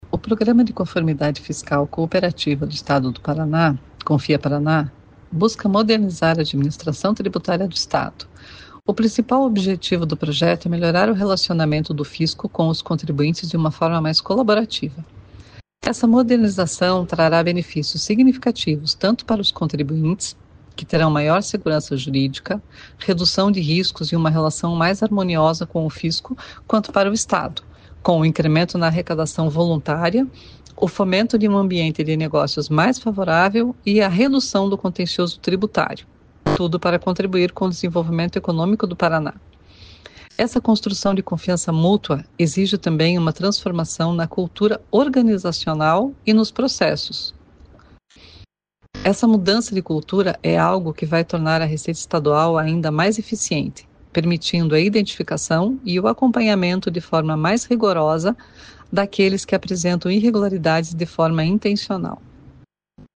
Sonora da diretora da Receita Estadual, Suzane Gambetta, sobre a regulamentação do programa Confia Parana